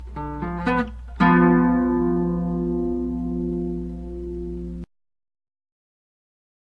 • The Bajo Sexto is a twelve-string guitar.
G 7th chord